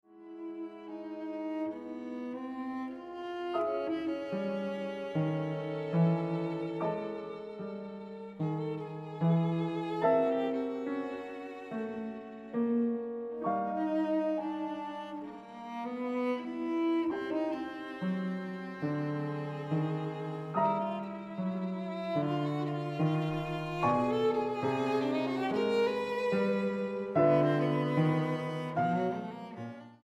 Grabado del 2 al 6 de Septiembre de 2013, Sala Xochipilli
Piano: Bechstein